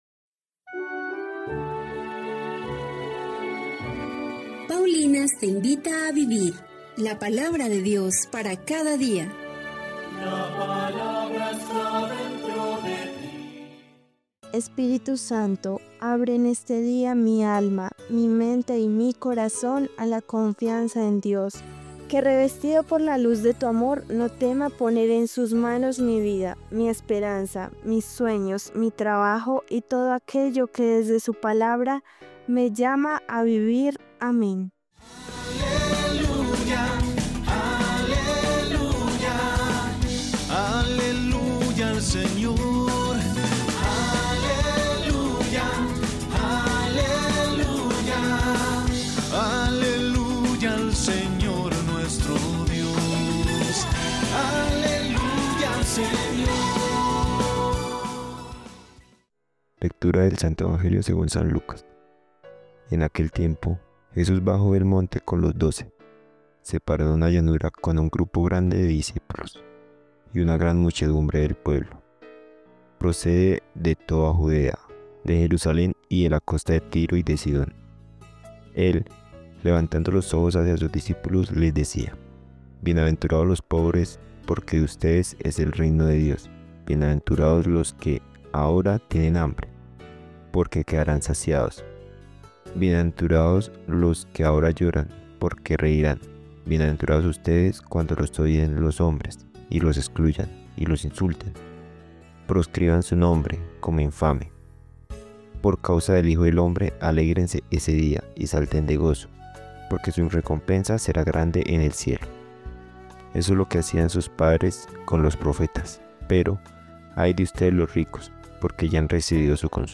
Liturgia-16-de-Febrero.mp3